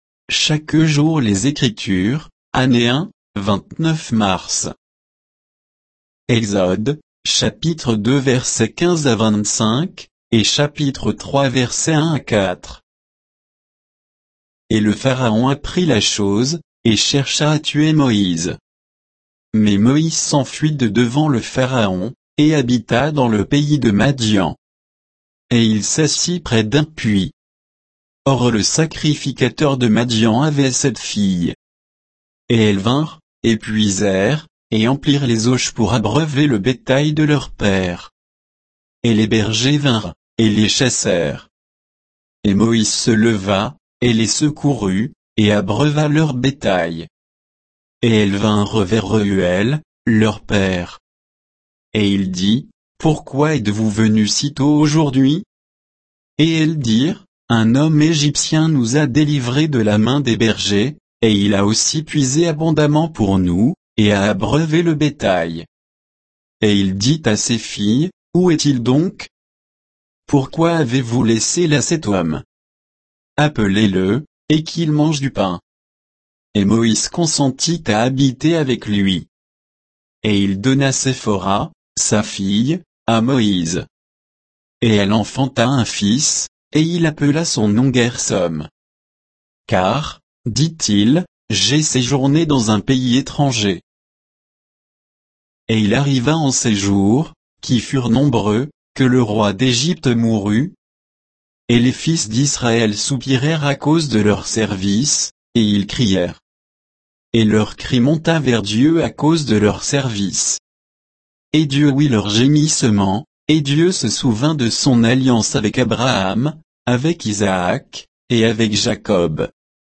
Méditation quoditienne de Chaque jour les Écritures sur Exode 2, 15 à 3, 6